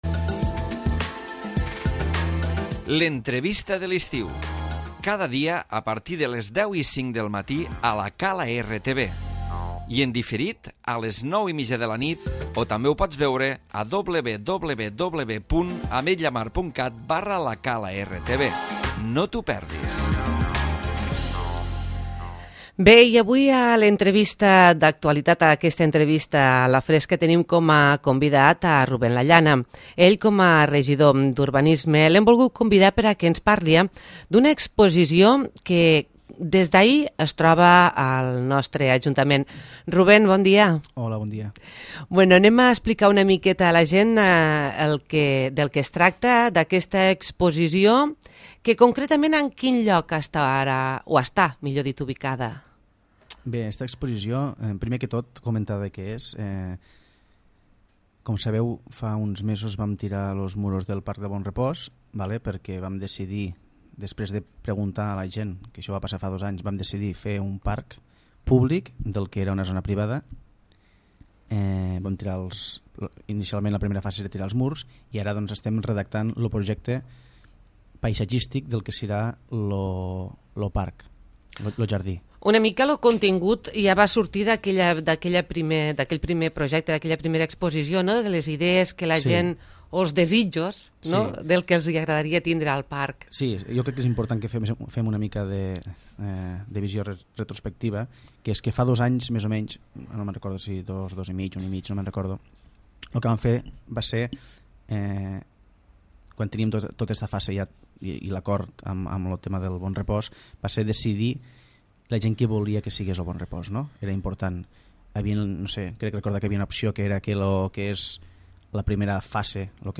L'Entrevista
Ruben Lallana , regidor d'urbanisme de l'Ajuntament de l'Ametlla de mar, ha parlat avui sobre la exposició pública del projecte del Parc del Bon Repòs.